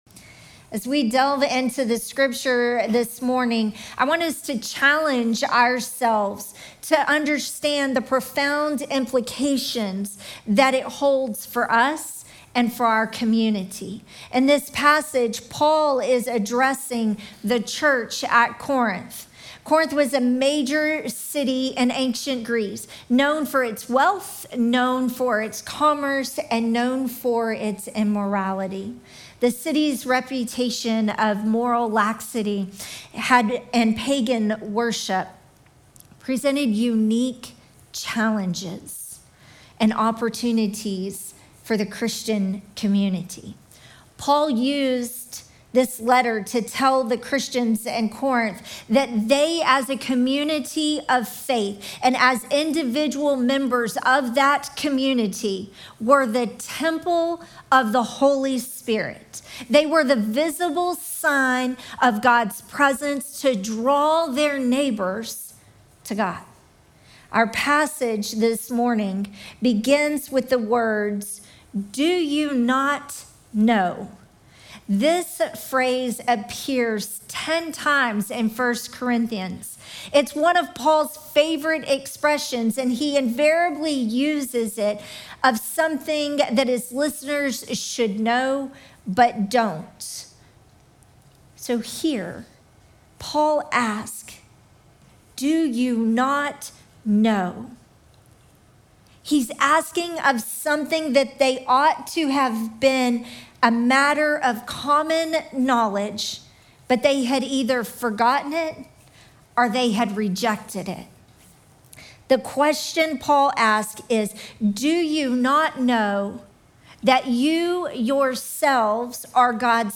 Sermon text: 1 Corinthians 3:16-17